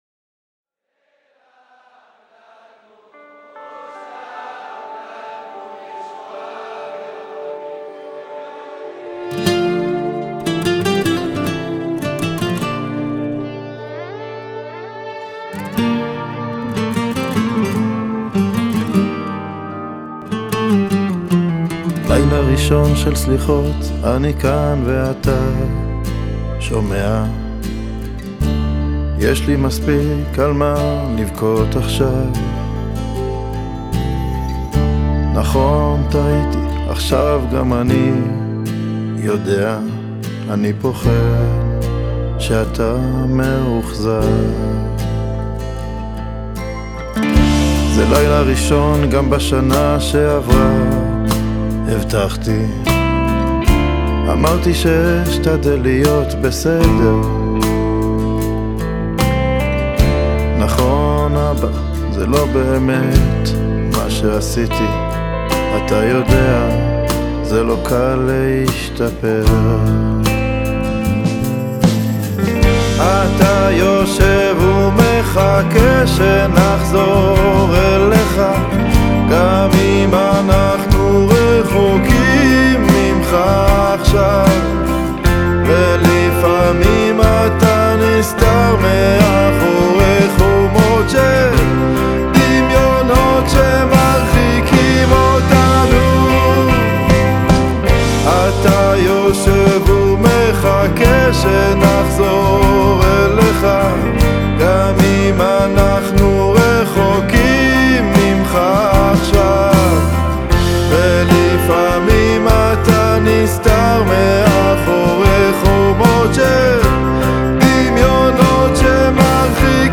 המילים והלחן רגישים, נוגעים ומבטאים עומק ועושר מוזיקלי.
ובגיטרות